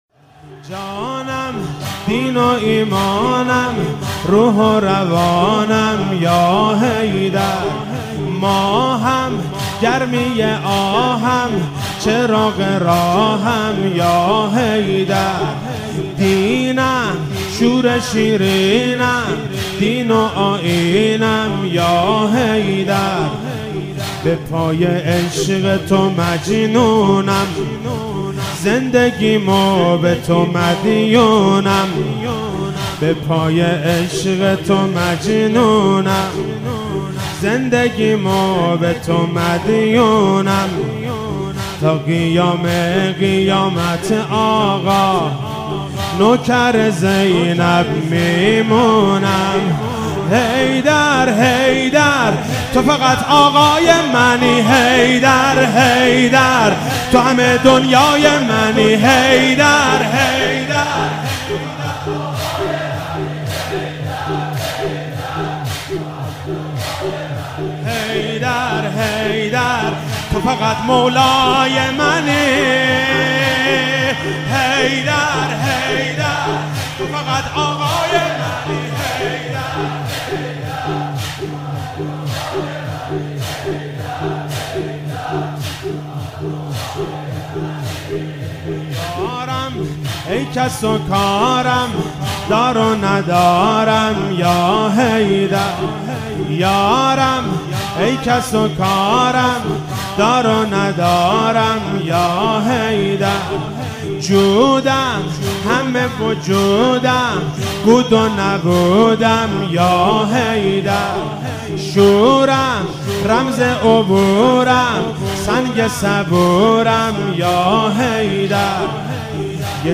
شب 20 ماه مبارک رمضان 96(قدر) - زمینه - جانم روح و ایمانم روح و روانم
مداحی